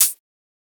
xTMinus-HH.wav